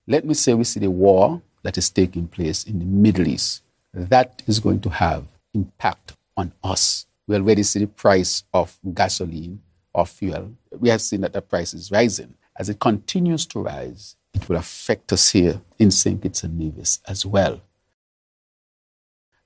But what measures will the Government put in place to cushion the impact, potentially for vulnerable households and businesses? In response to a journalist on Mar. 10th, PM Drew said the Government will protect its people.